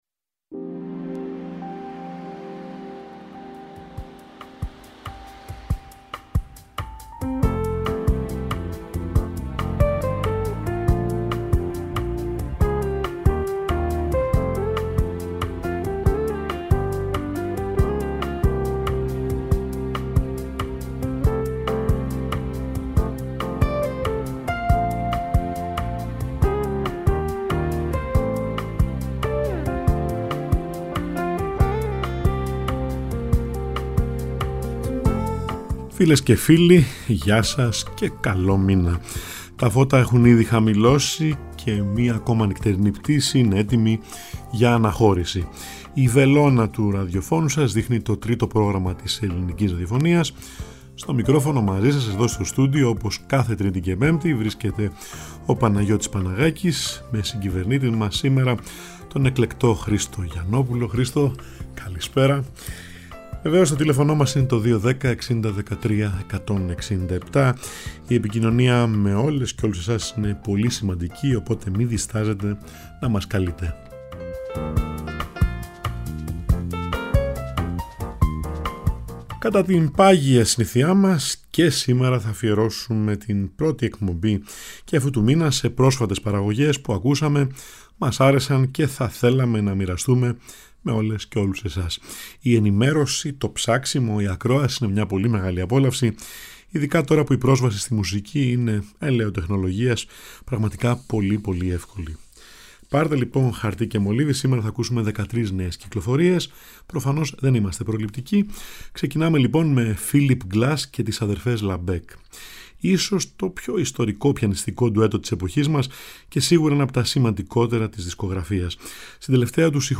εξαιρετικά ρεσιτάλ για βιολί
σόλο πιάνο (σε δικές του συνθέσεις)
πρώιμα έργα